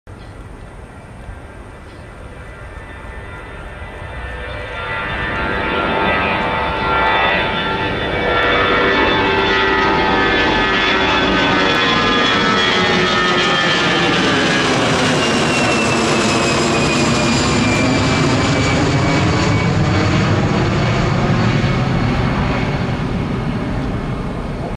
Takeoff from Miami 🇺🇸 ✈ sound effects free download
Airbus A319-114 Air Canada Rouge
📍 aeropuerto Intl Miami